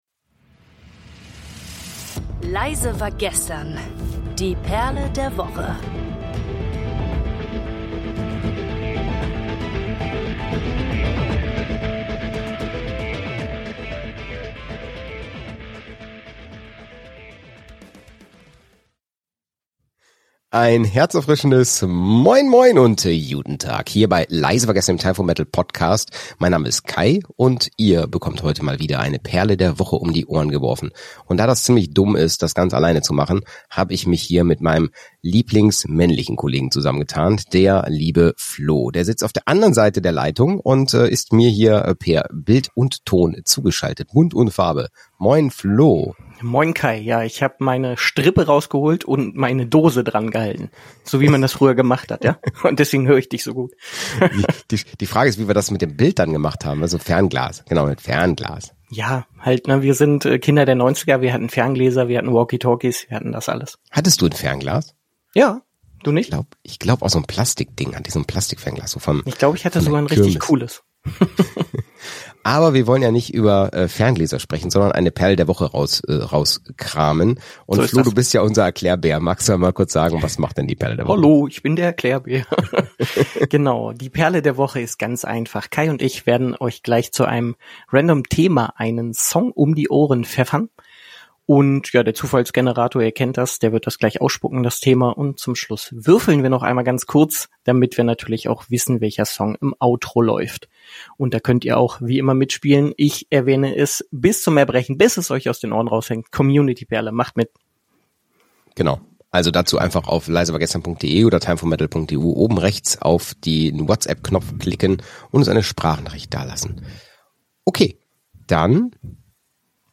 In dieser Folge des Time for Metal Podcasts spielen die Moderatoren das Spiel 'Perle der Woche', bei dem sie jeweils einen Song zu einem zufällig generierten Buchstaben suchen.